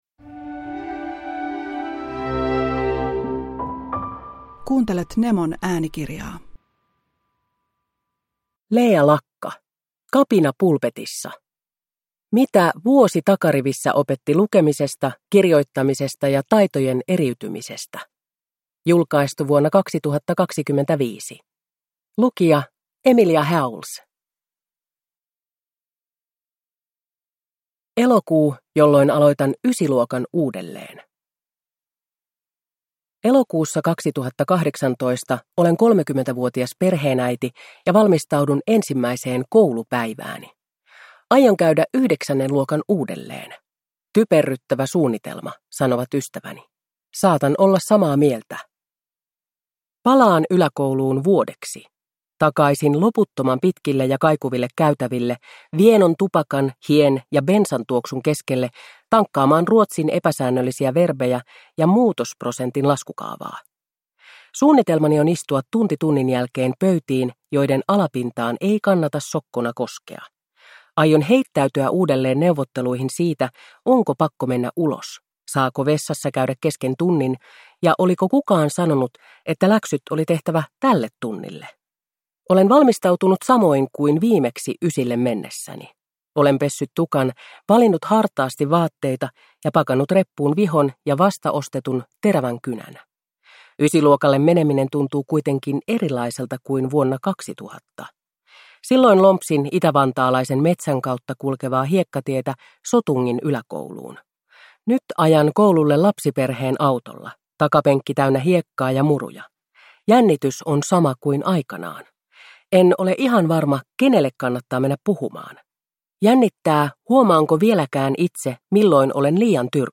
Kapina pulpetissa – Ljudbok